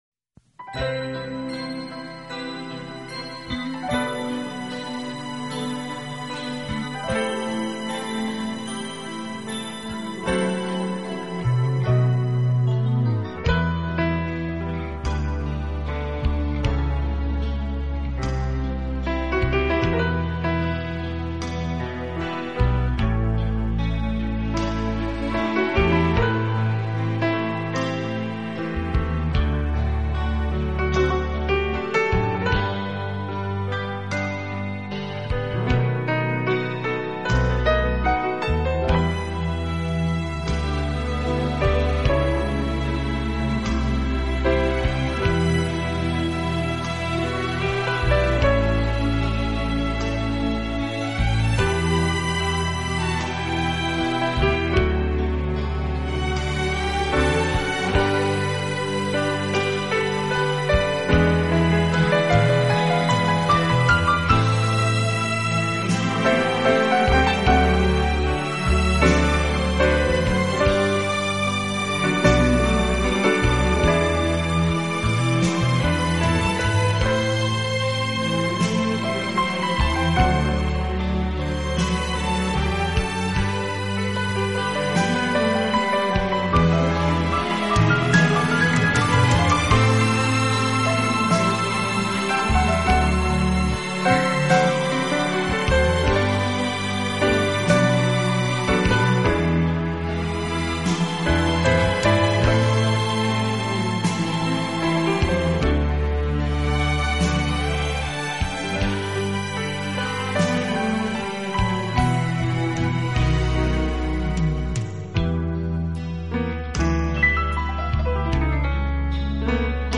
而缠绵悱恻。
本套CD全部钢琴演奏，